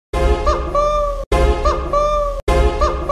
I Phone Ringtones